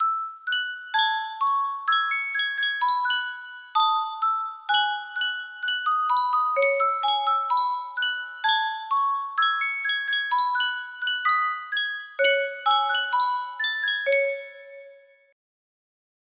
Sankyo Music Box - Hotaruno Hikari GMR music box melody
Full range 60